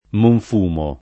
[ monf 2 mo ]